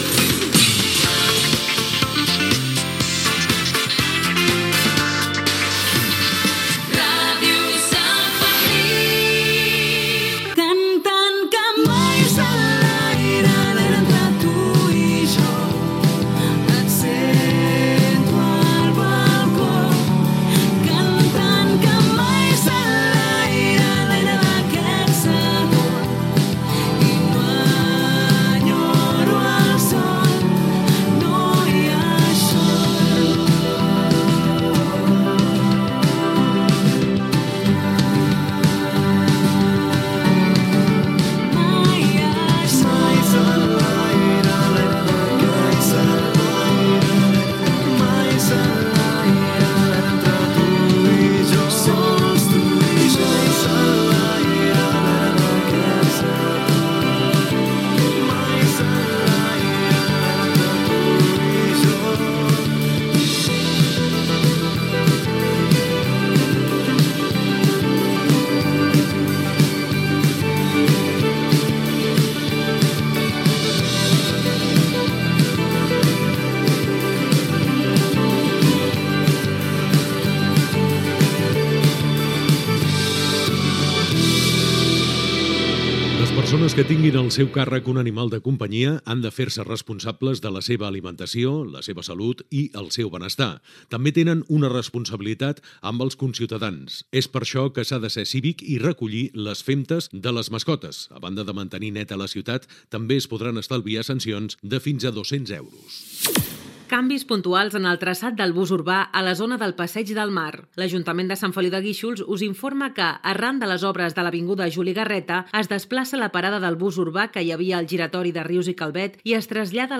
Indicatiu de l'emissora, tema musical, comunicats de l'Ajuntament.
Informatiu
FM